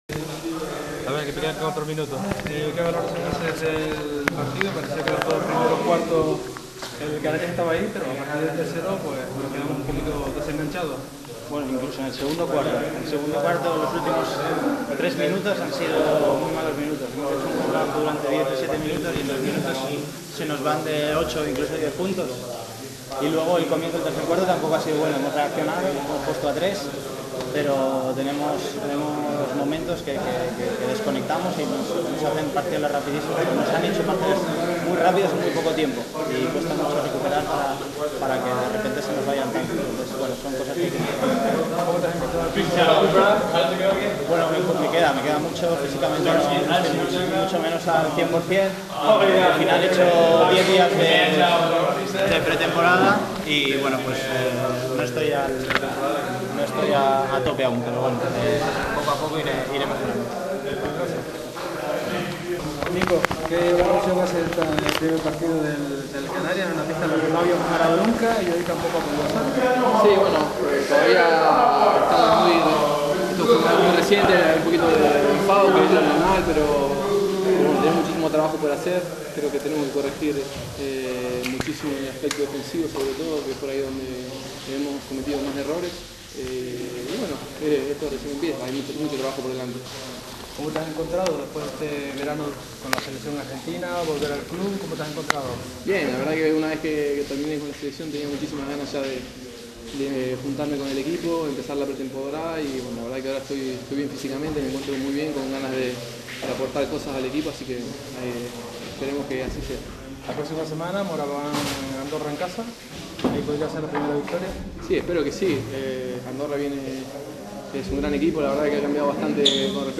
La apertura de vestuarios, que aprobó la última Asamblea General de la Liga ACB, se puso en práctica el pasado sábado, así que SuperBasket Canarias pudo acceder al vestuario aurinegro en el Palau Olímpic de Badalona.
Allí, entre sudor, toallas y desencanto por la derrota, pudimos charlar con Rodrigo San Miguel y Nico Richotti, que nos atendieron amablemente.